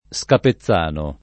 Scapezzano [ S kape ZZ# no ]